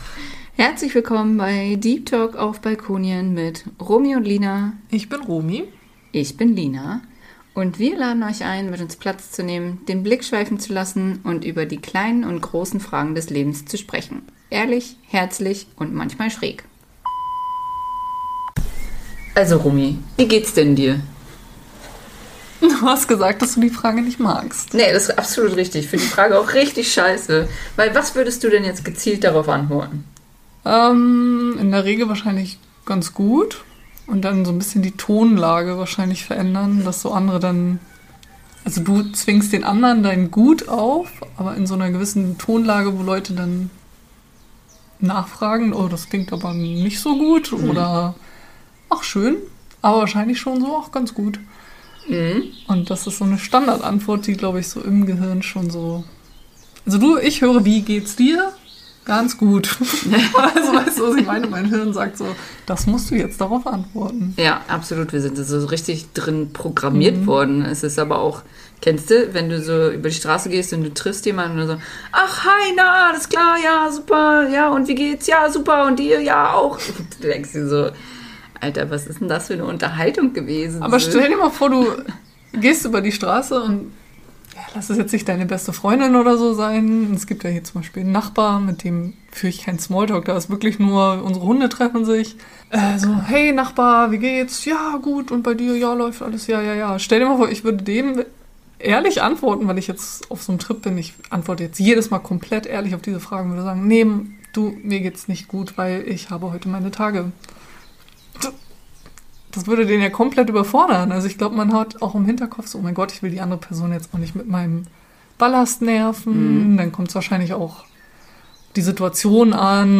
In dieser Folge sprechen wir ehrlich über Gefühlsschwankungen, mentale Gesundheit und den Mut, sich verletzlich zu zeigen. Ein ehrliches Gespräch über Tiefs, Selbstreflexion und warum es so wichtig ist, offen über mentale Themen zu sprechen.